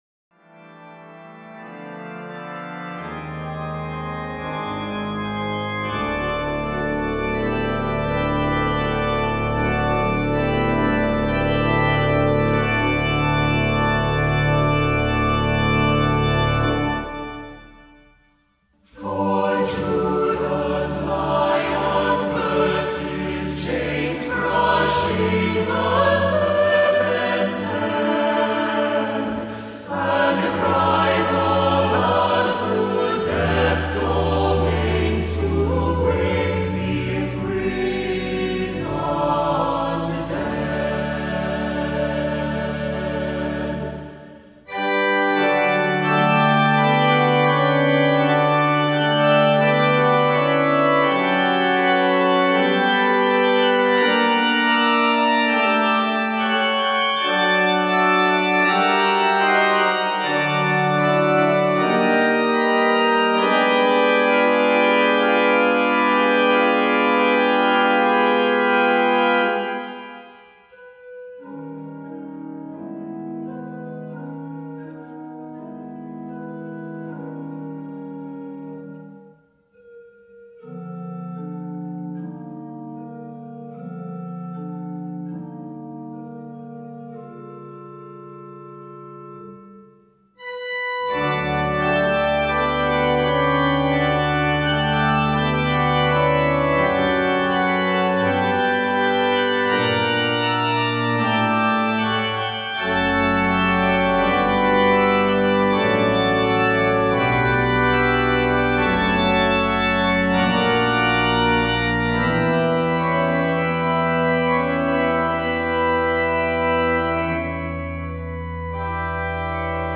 Voicing: SATB; Assembly